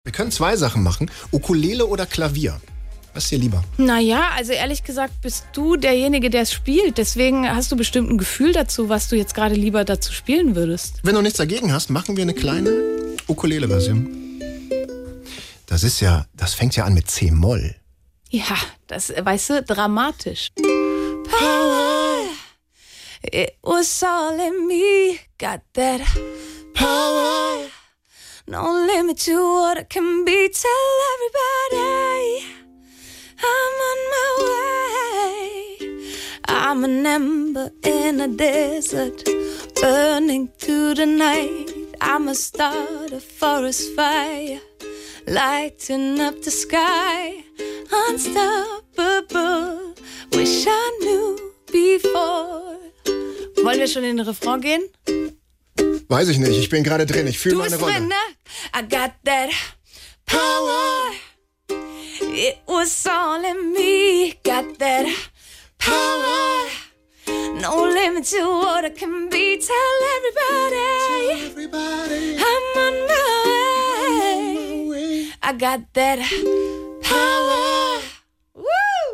mit Ukulele